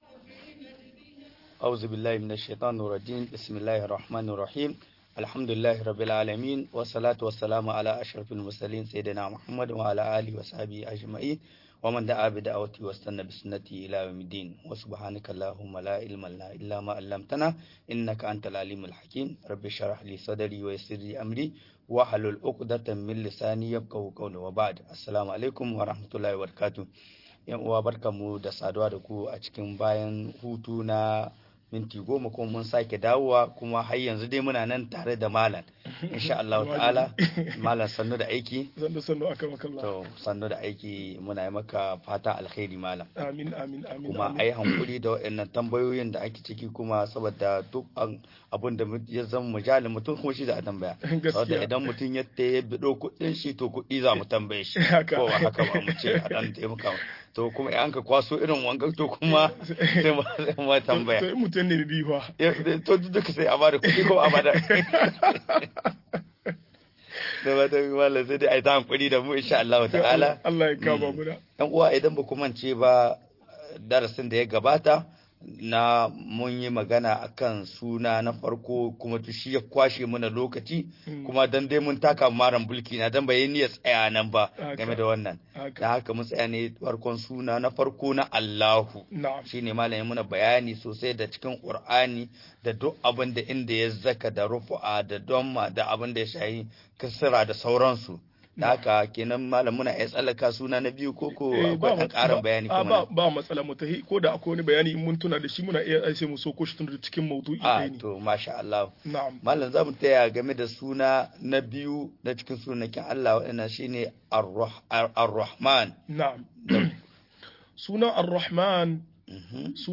Sunayen Allah da siffofin sa-04 - MUHADARA